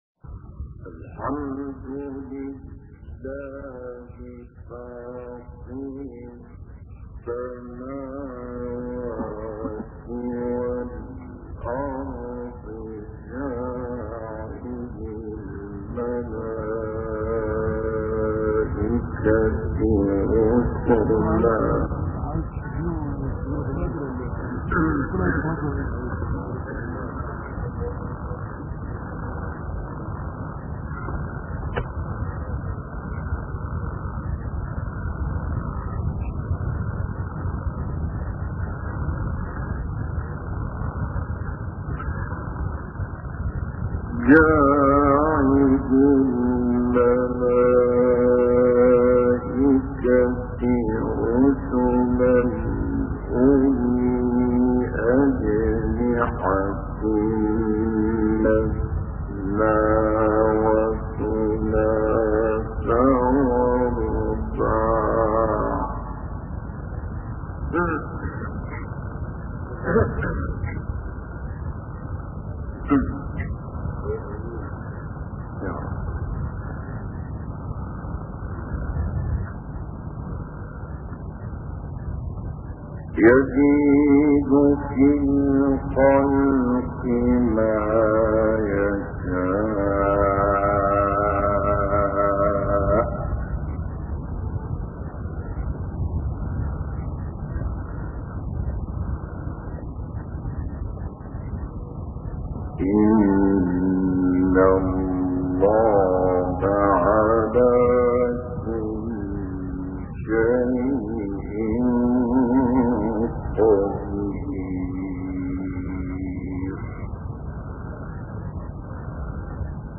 گروه شبکه اجتماعی: تلاوتی از سوره مبارکه فاطر و تلاوتی از سوره مبارکه رعد، با صوت شیخ نصرالدین طوبار را می‌شنوید.
تلاوت آیاتی از سوره رعد